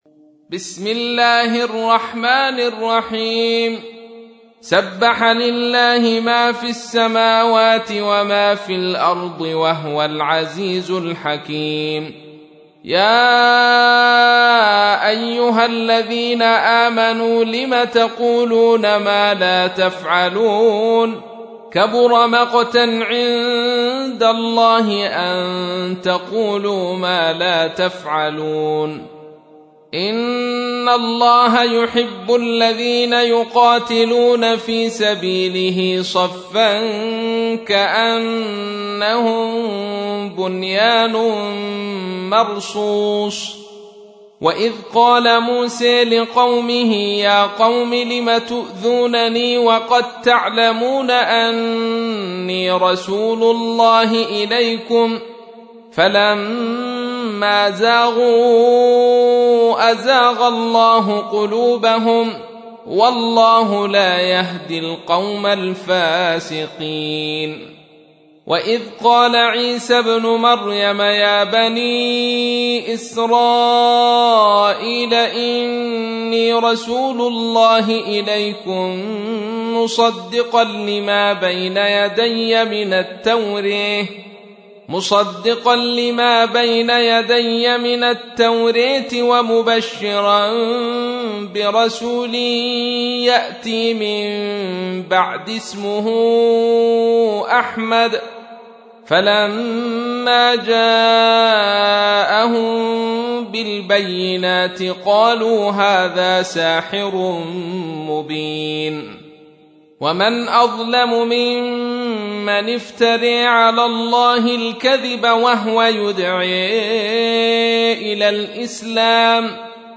تحميل : 61. سورة الصف / القارئ عبد الرشيد صوفي / القرآن الكريم / موقع يا حسين